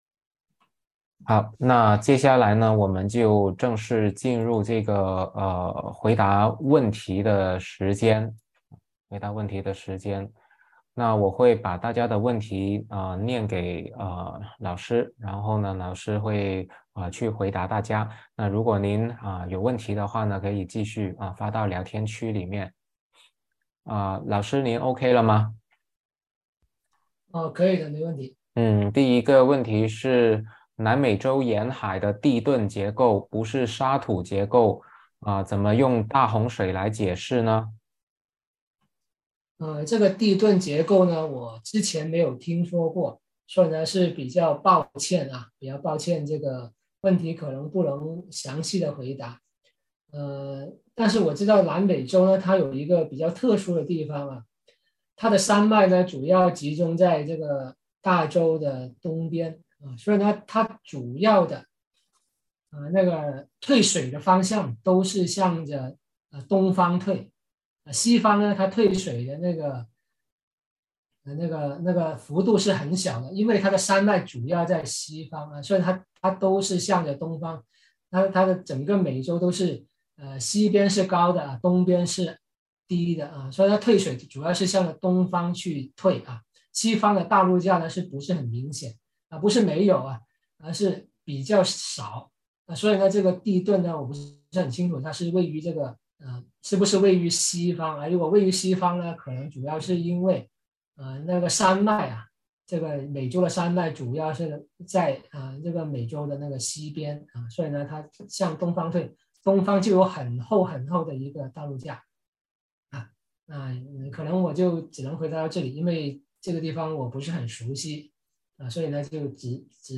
《桂林科学之旅》讲座直播回放